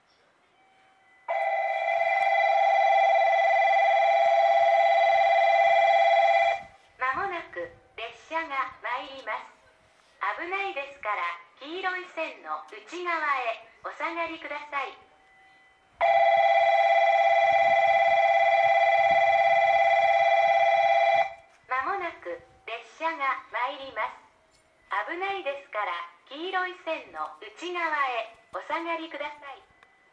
この駅では接近放送が設置されています。
３番のりば日豊本線
接近放送普通　鹿児島中央行き接近放送です。